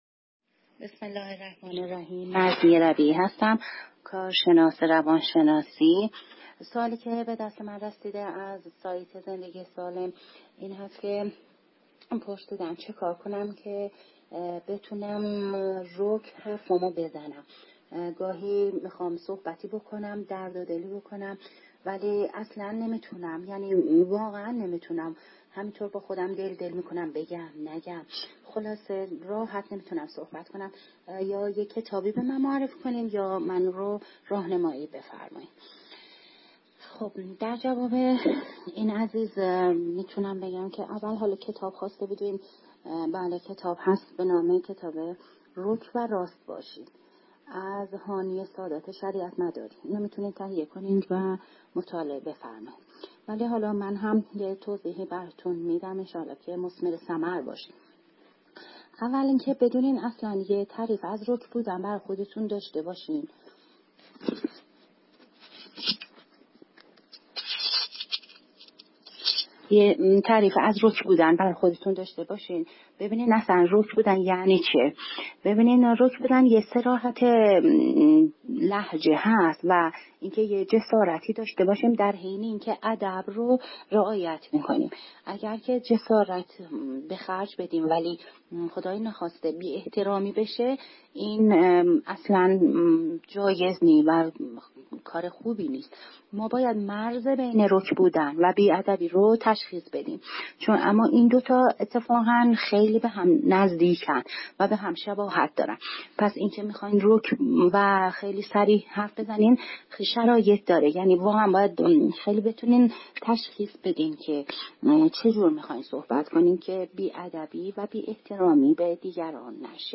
مشاوره صوتی